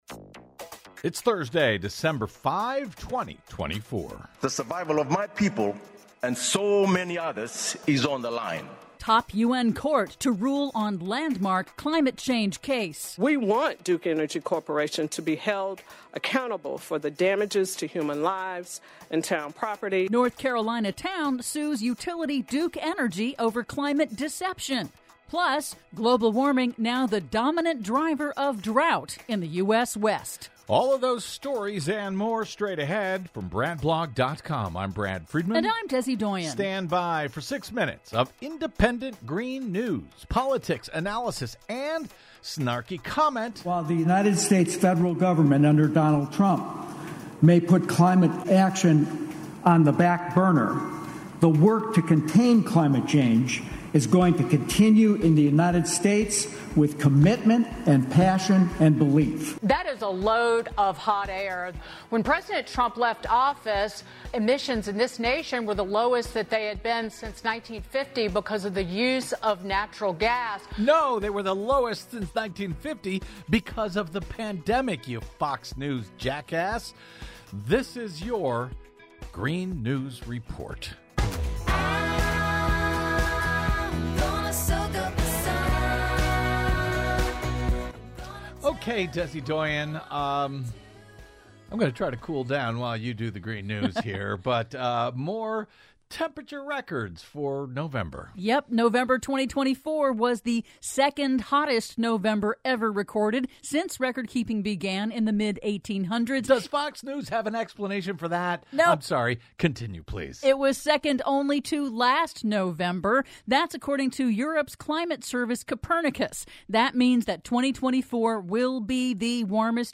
IN TODAY'S RADIO REPORT: Top U.N. court to rule on landmark climate case; North Carolina town sues Duke Energy over climate deception; South Africa's High Court blocks new coal plants; PLUS: Global warming now the dominant driver of drought in the U.S. West... All that and more in today's Green News Report!